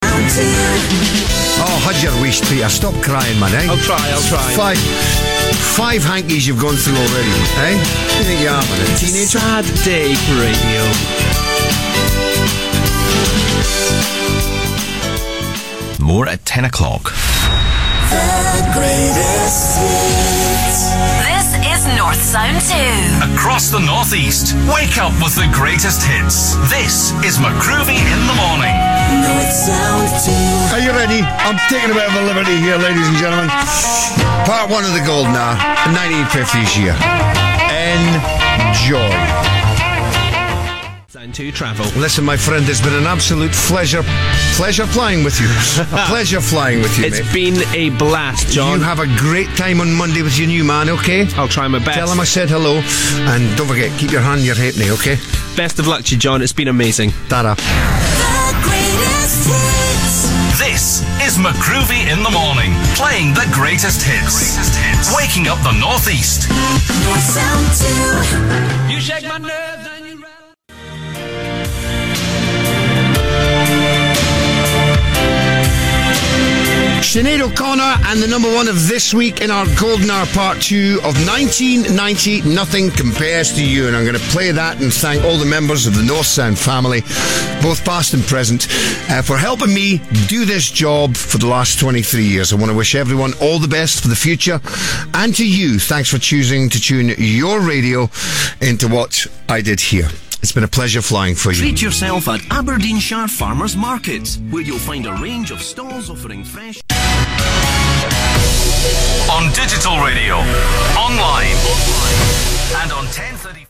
End of local programming on Northsound 2 - 2013